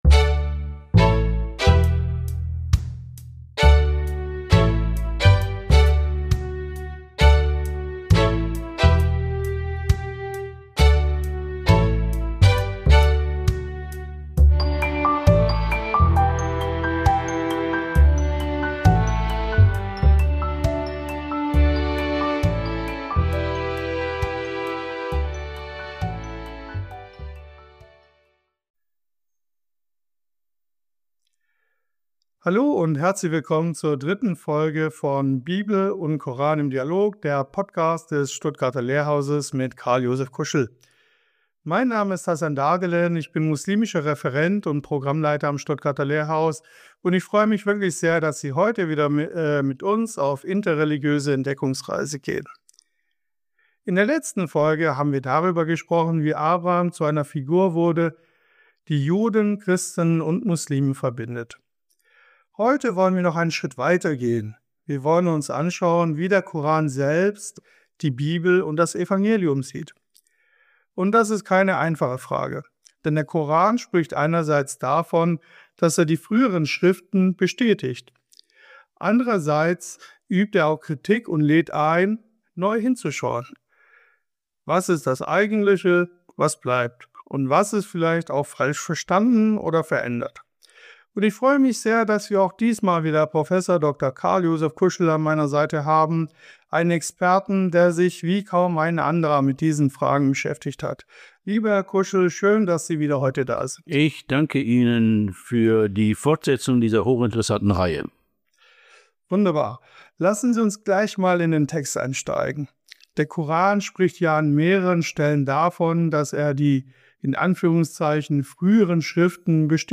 Was bedeutet es, wenn der Koran die früheren Schriften bestätigt, aber gleichzeitig eigene Akzente setzt? Welche Bibel ist gemeint – und wie liest der Koran das christlich-jüdische Bucherbe? Das Gespräch führt tief in theologische Grundfragen, aber auch in konkrete Missverständnisse und Konflikte der Auslegung.